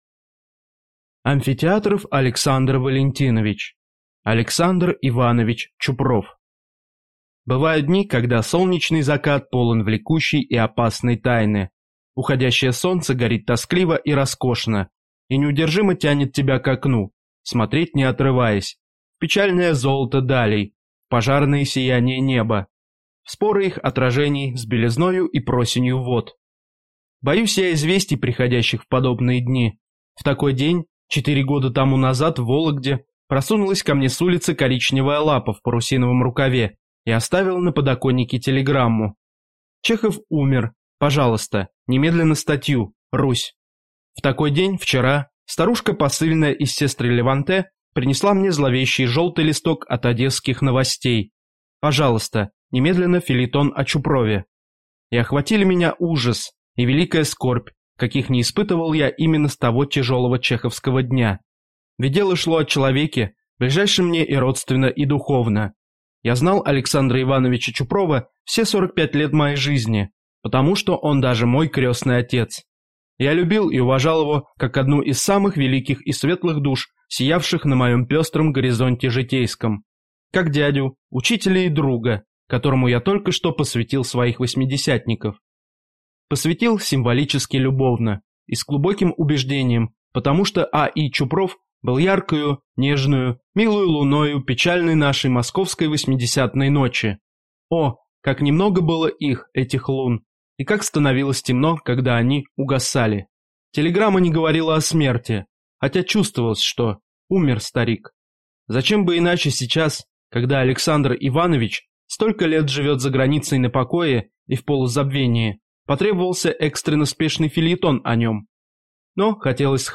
Аудиокнига Александр Иванович Чупров (II) | Библиотека аудиокниг